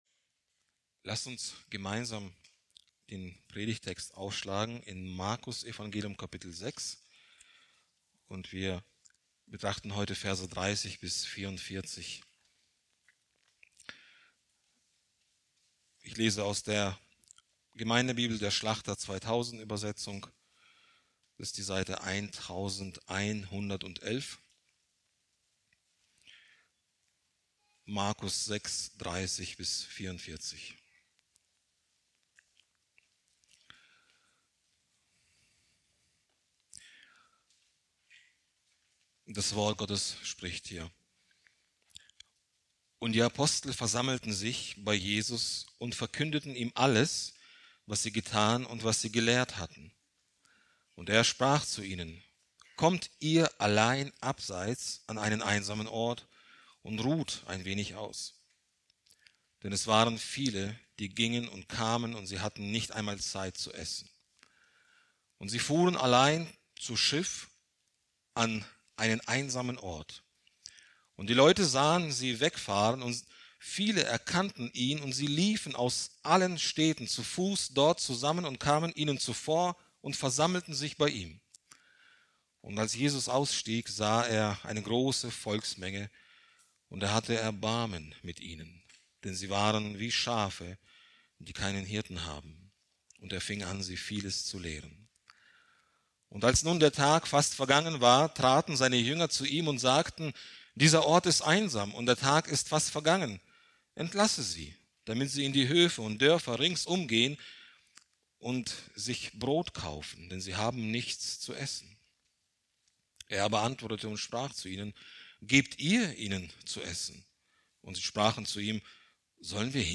Predigt aus der Serie: "Evangelium nach Markus"